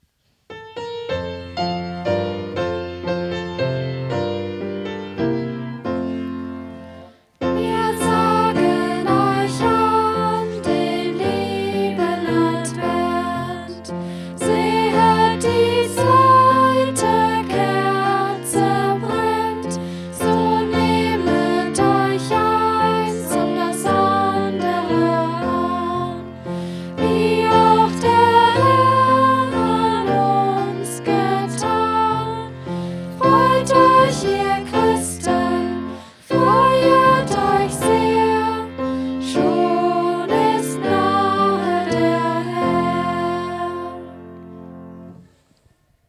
Lied:
gesungen von den Schülerinnen der SacroPop-AG; am Flügel